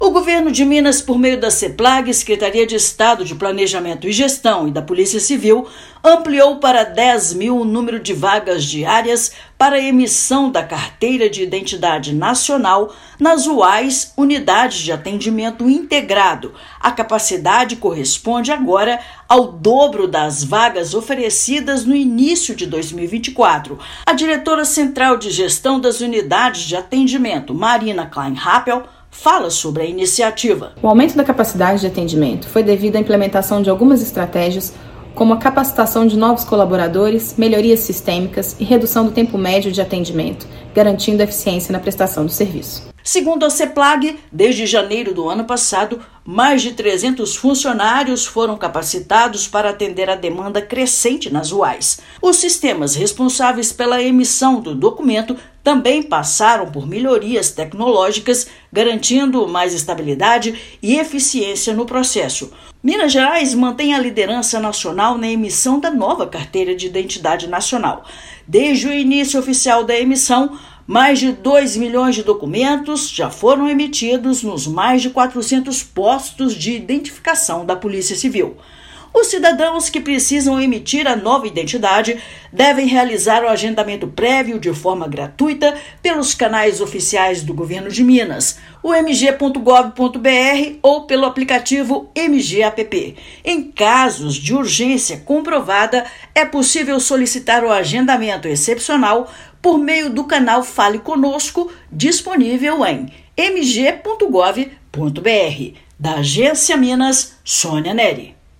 Já são 10 mil vagas para emissão da CIN, o dobro da capacidade oferecida no início de 2024. Ouça matéria de rádio.